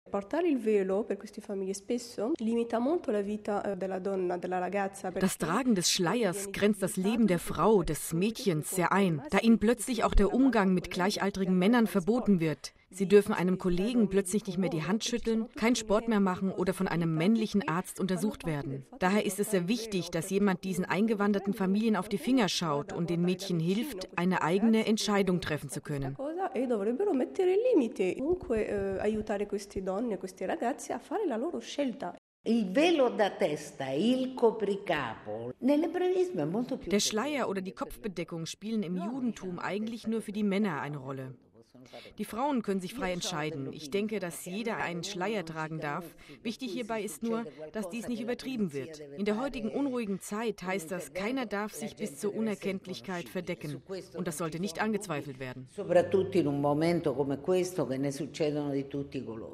Bei dem Diskussionsforum kamen auch christliche und jüdische Gelehrte zu Wort.